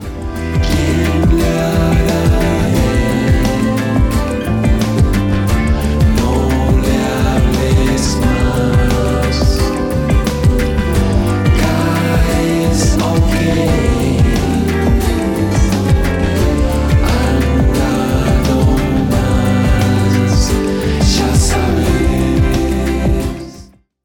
Grabado en casa.
Guitarras